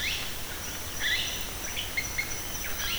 Cacicus cela
Yellow-rumped Cacique